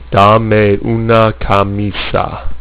This is because of the dead space that is inherent at the start and end of file, due to the delay between recording beginning and the speech sample starting (and similarly at the end).